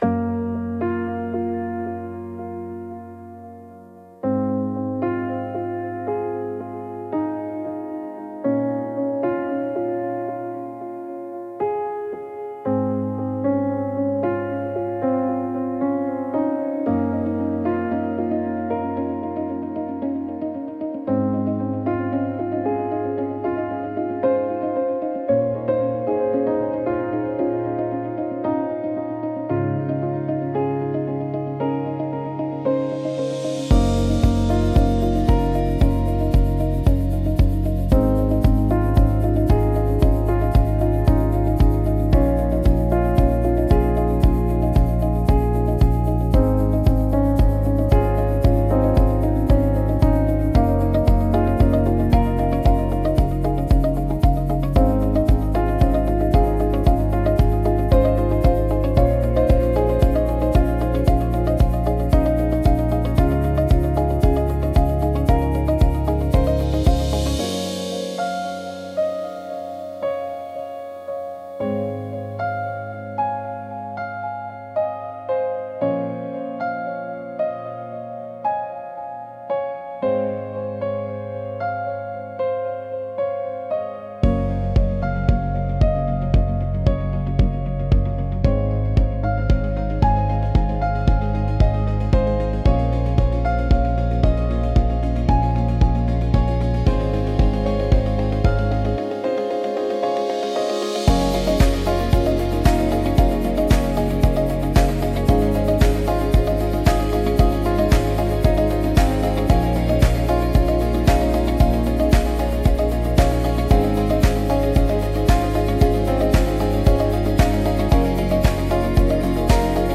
Instrumental: (Remastered)